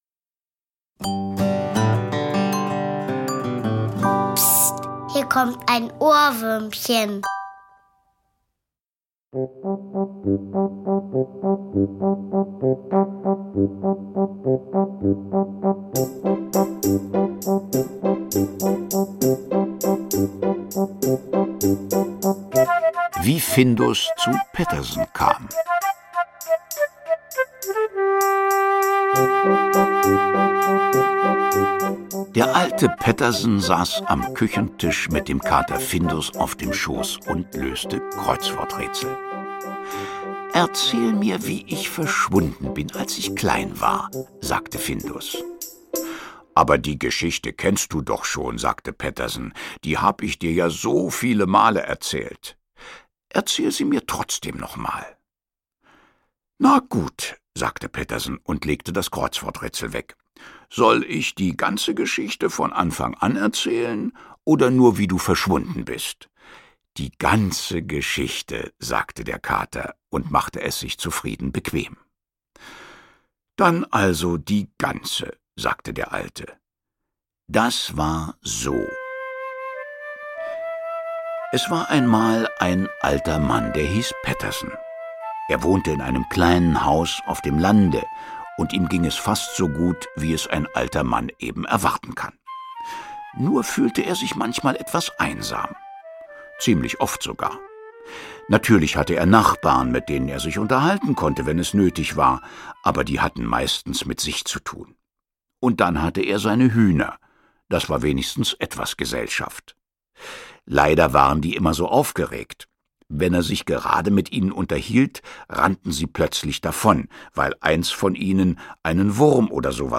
Hörbuch: Pettersson und Findus.